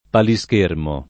palischermo [ pali S k % rmo ]